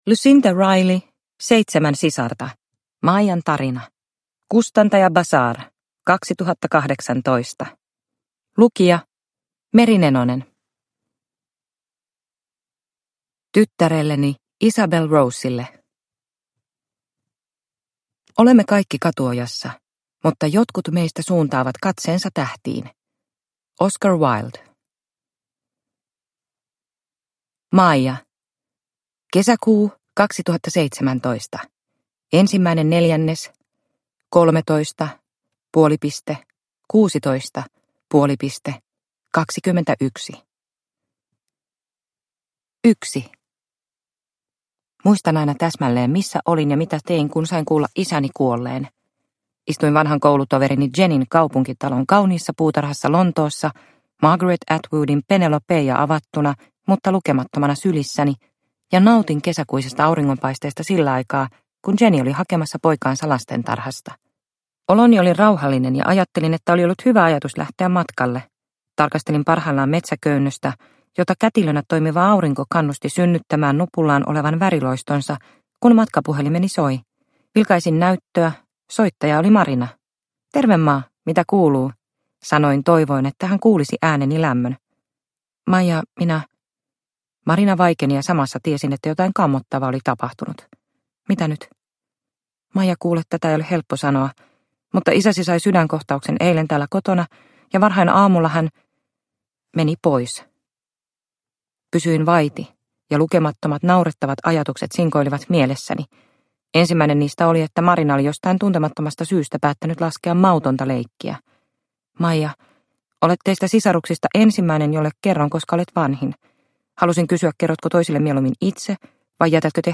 Seitsemän sisarta – Ljudbok – Laddas ner